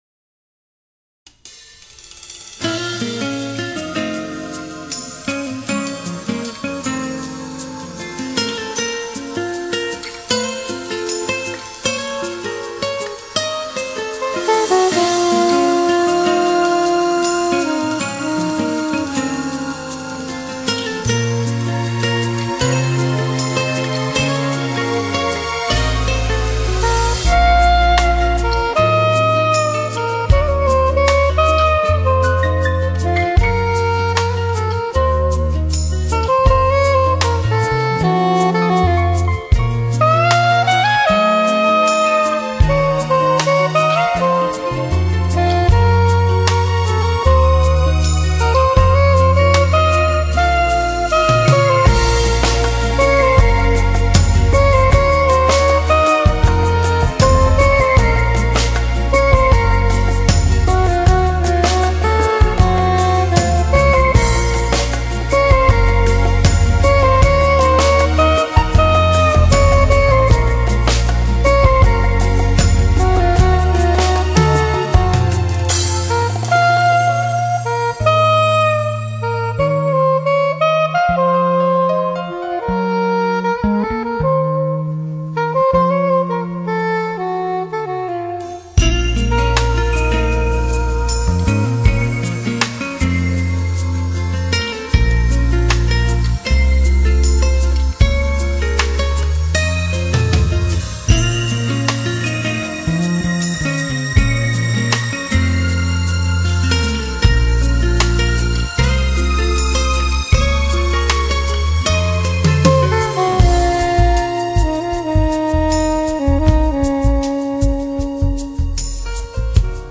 這是一張充滿羅曼蒂克情懷的作品，浪漫的，奇情的，想像的，優雅的， 慵懶的，